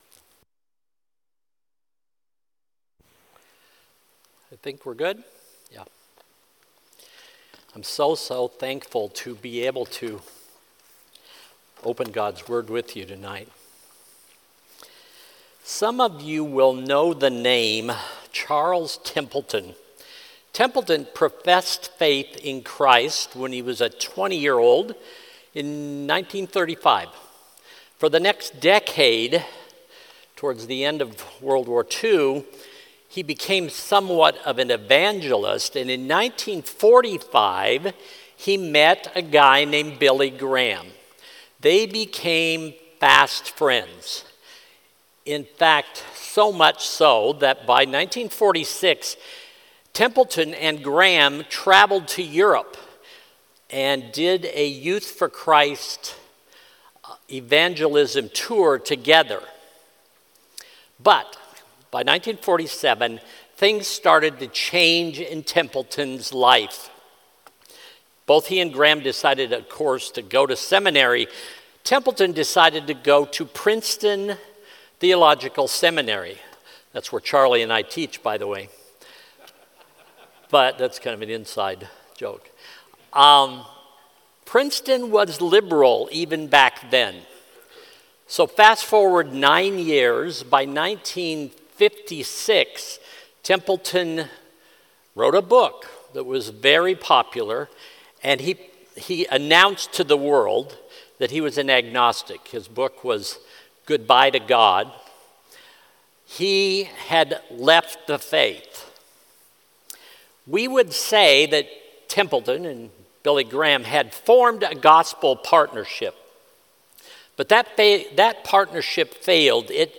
Sermons
sunday-evening-1-12-25.mp3